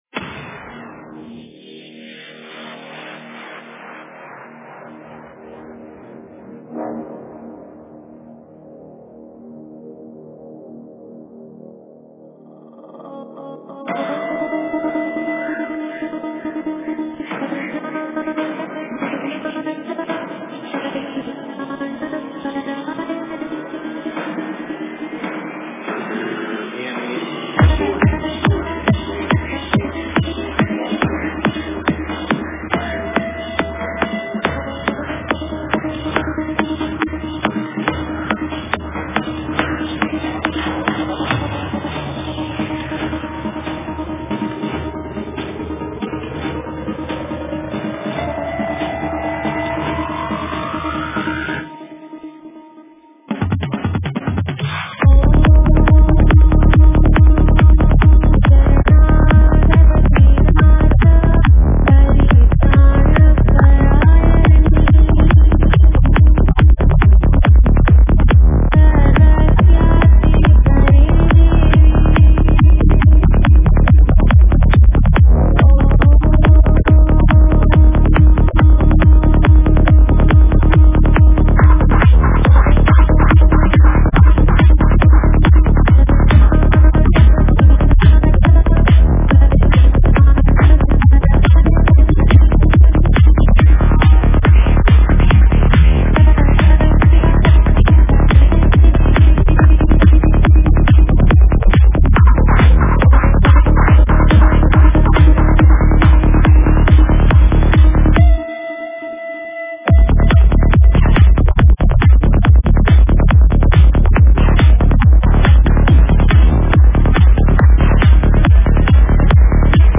Файл в обменнике2 Myзыкa->Psy-trance, Full-on
Стиль: Psy Trance